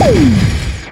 hero_hoverboard_crash.ogg